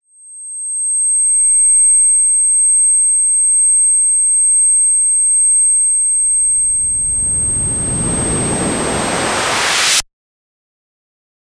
blown-ear-drum deaf drone ear ears effect explosion frequency sound effect free sound royalty free Memes